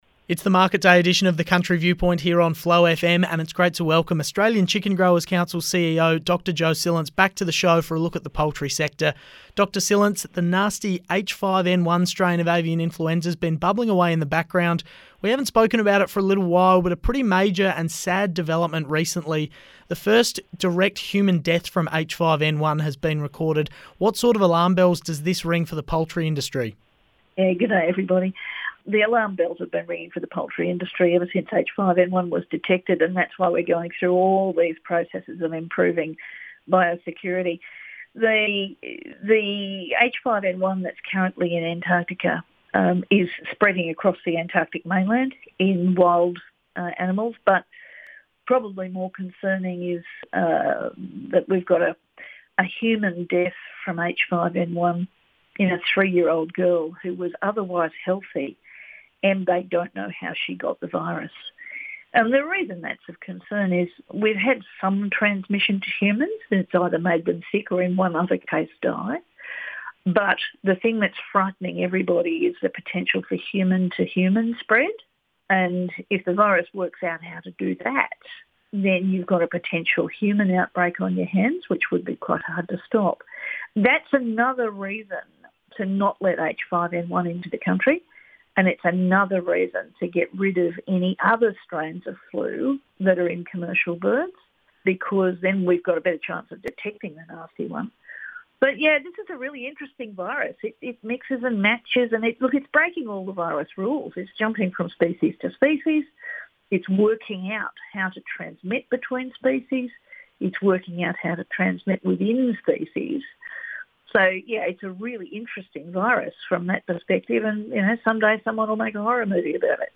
Radio Interviews on Flow FM, South Australia